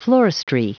Prononciation du mot floristry en anglais (fichier audio)
Prononciation du mot : floristry